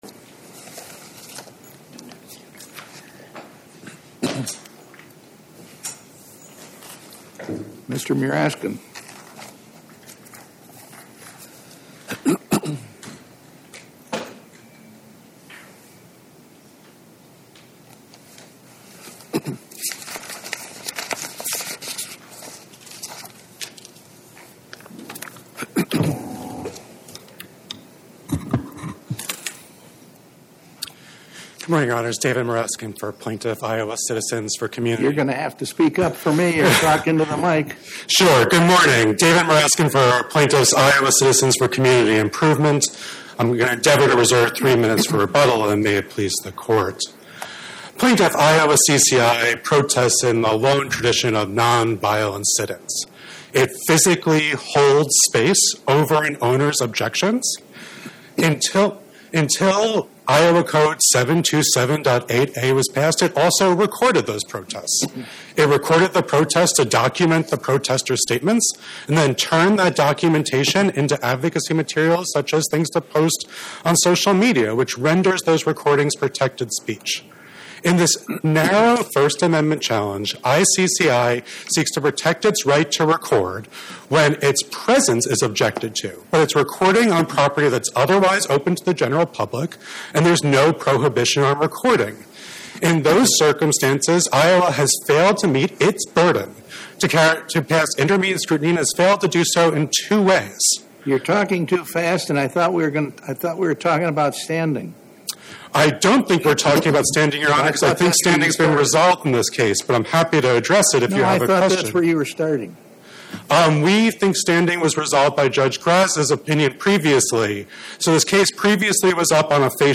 Oral argument argued before the Eighth Circuit U.S. Court of Appeals on or about 01/14/2026